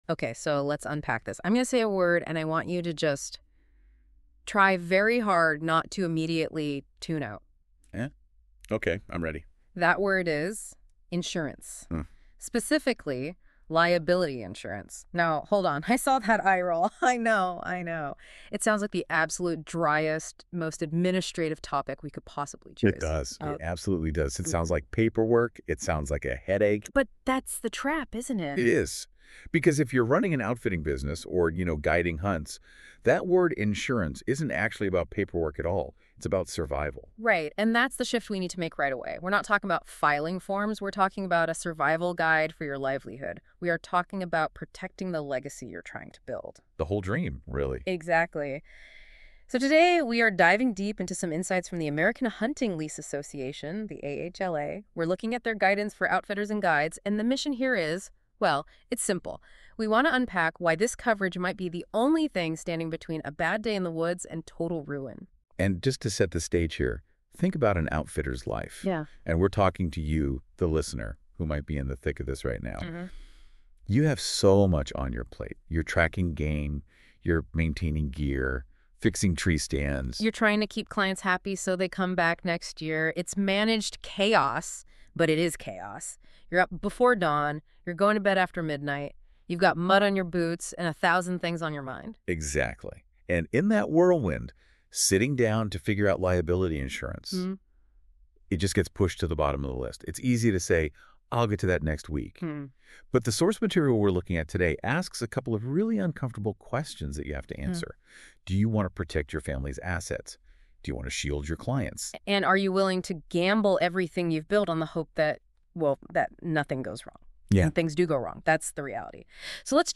Audio summary